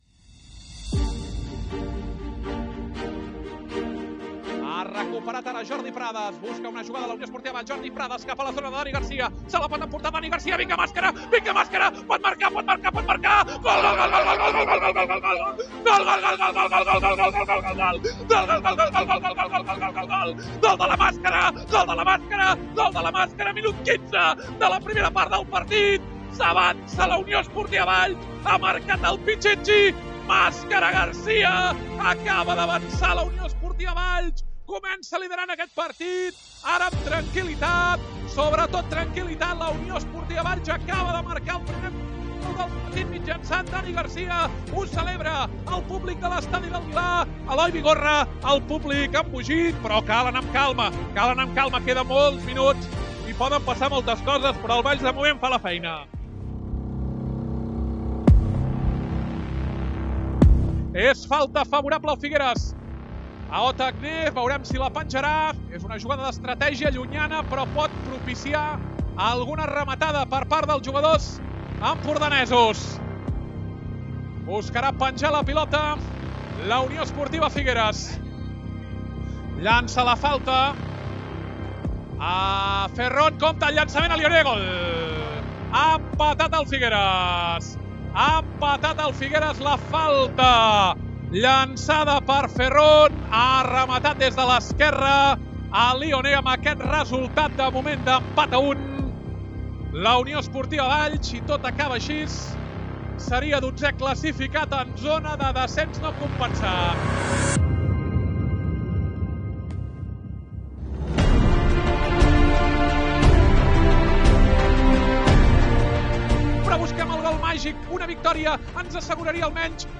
El conjunt blanc-i-vermell es va exhibir contra la Unió Esportiva Figueres per 4 a 1 en un partit trepidant i amb final feliç. A Ràdio Ciutat de Valls, fidels des del 2020 amb les transmissions del primer equip de futbol de la ciutat, us oferim un resum sonor d’un enfrontament memorable.